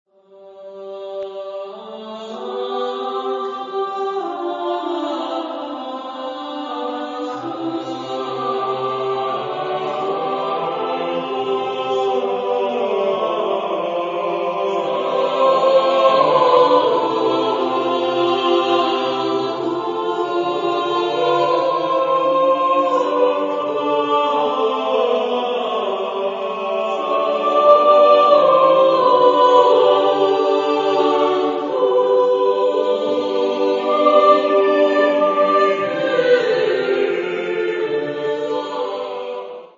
Genre-Style-Forme : Messe ; Sacré
Type de choeur : SATB  (4 voix mixtes )
Tonalité : mode de fa